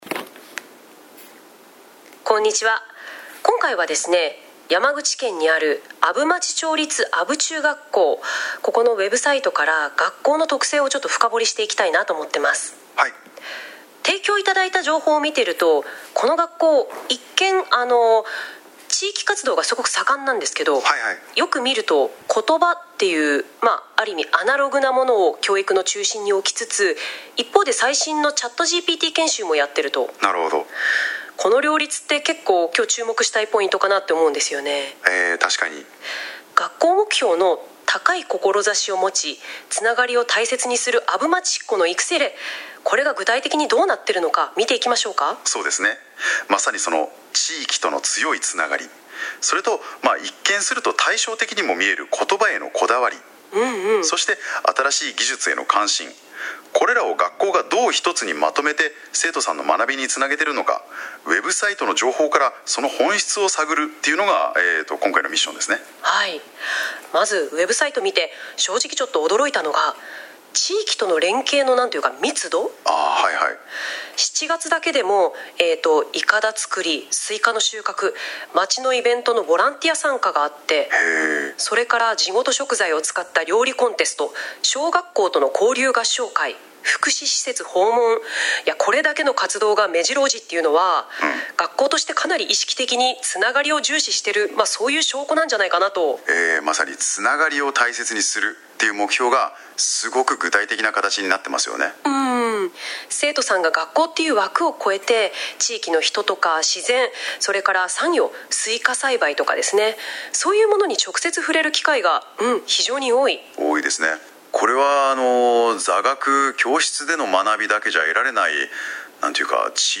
本年度の１学期の取組に対する「生成AI」の論評です・・・
生成AIによる阿武中の評価.mp3